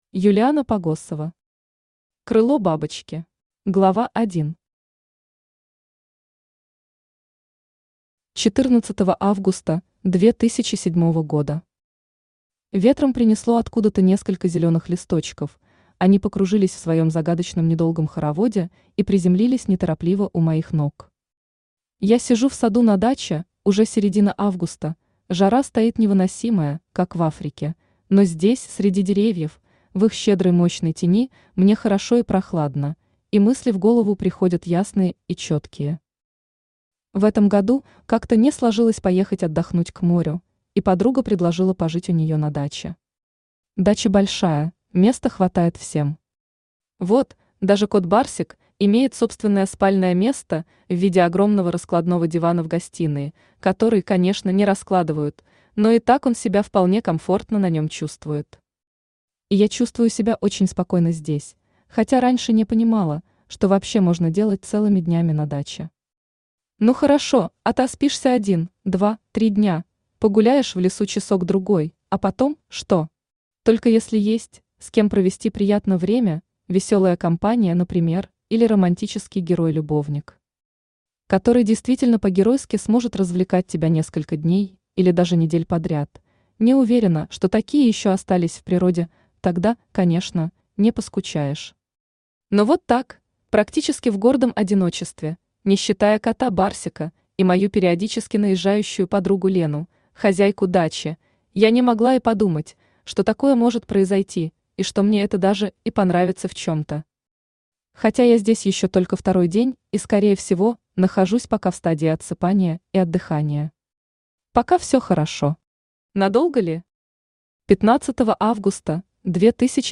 Аудиокнига Крыло бабочки | Библиотека аудиокниг
Aудиокнига Крыло бабочки Автор Юлиана Юрьевна Погосова Читает аудиокнигу Авточтец ЛитРес.